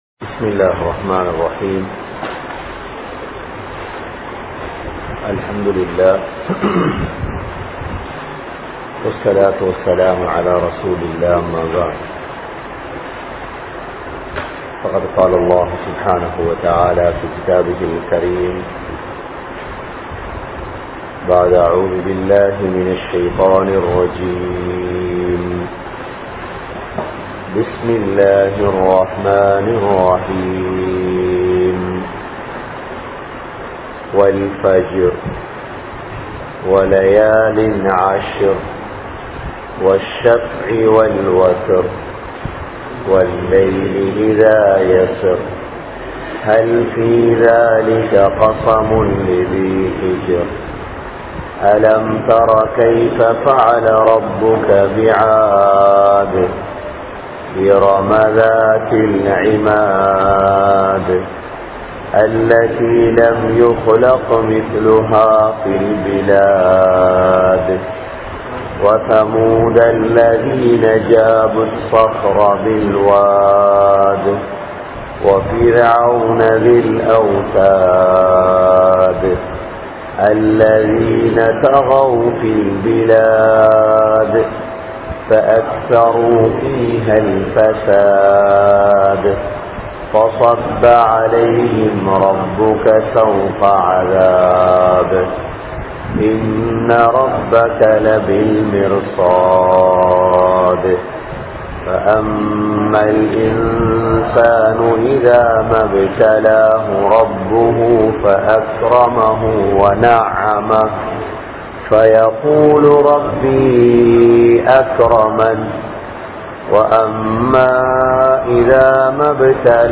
Surathul Fajr(Thafseer Part02) | Audio Bayans | All Ceylon Muslim Youth Community | Addalaichenai